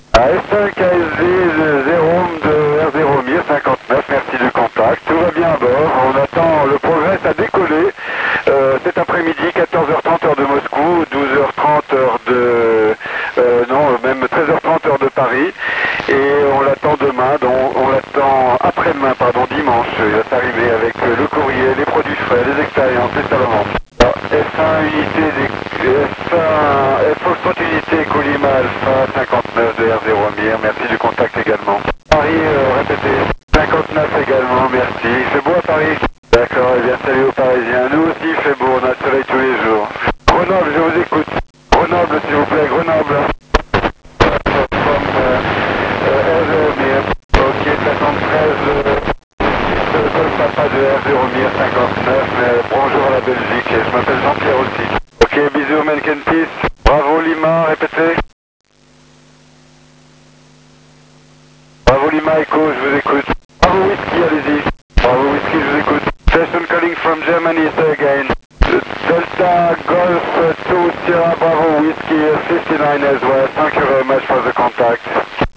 QSO PHONIE avec MIR